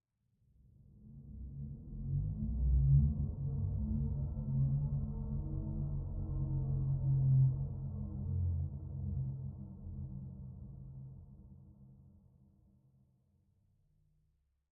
Minecraft Version Minecraft Version snapshot Latest Release | Latest Snapshot snapshot / assets / minecraft / sounds / ambient / underwater / additions / dark2.ogg Compare With Compare With Latest Release | Latest Snapshot